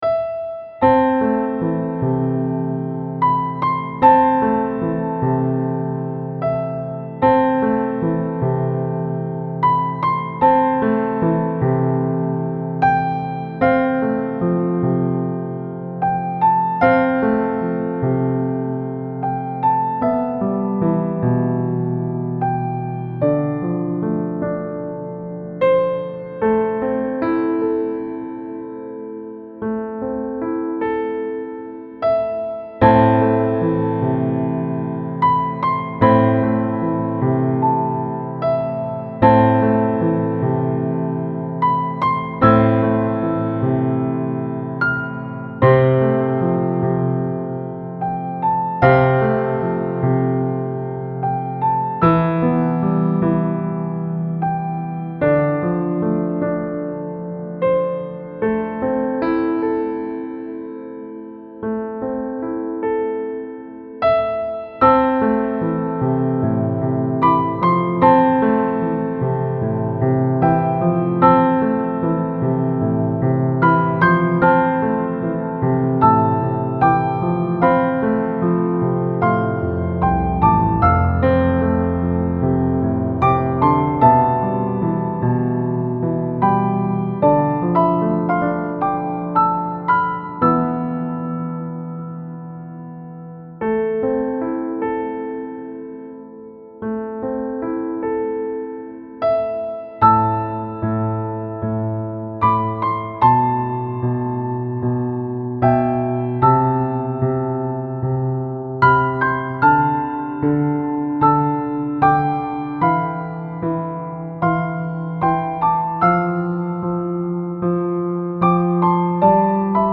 Style Style Soundtrack
Mood Mood Relaxed, Sad
Featured Featured Piano
BPM BPM 75